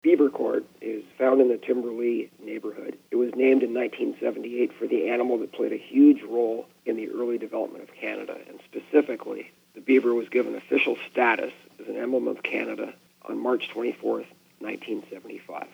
He was gracious enough to still do the interview this week despite suffering a throat injury during a hockey game.